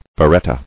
[be·ret·ta]